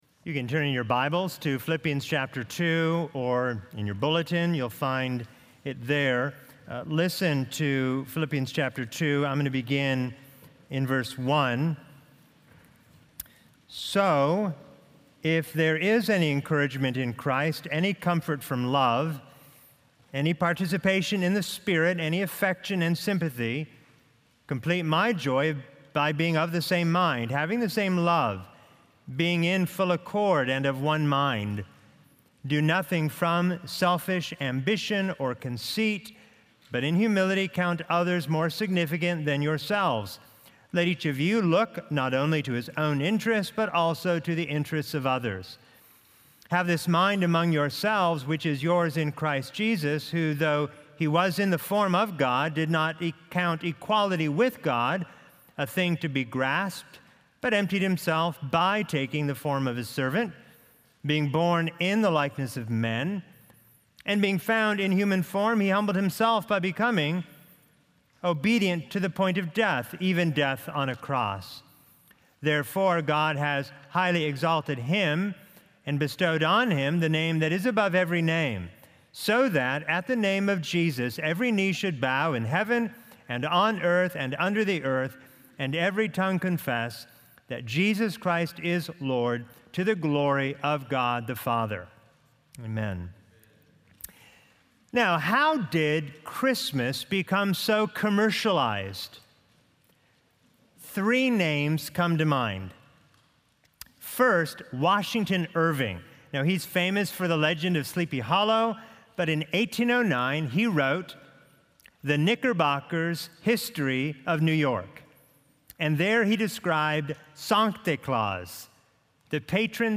Livestream Video & Sermon Audio